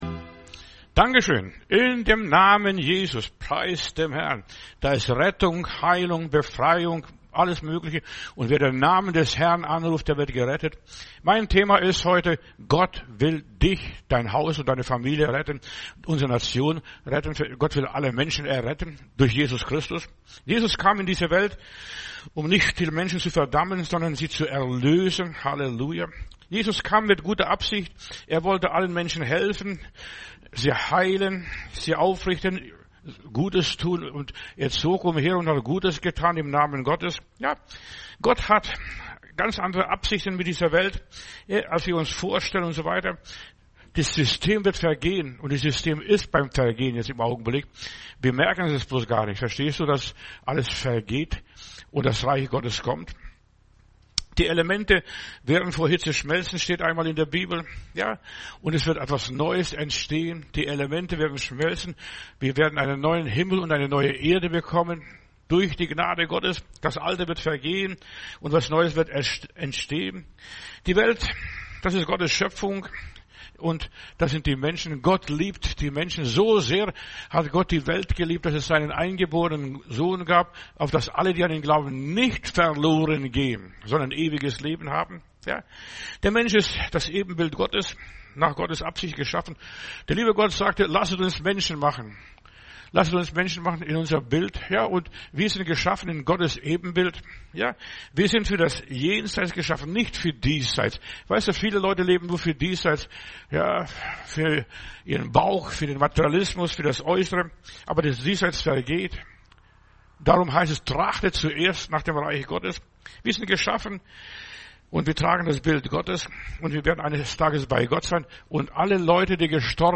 Predigt herunterladen: Audio 2024-11-23 Gott will dich retten Video Gott will dich retten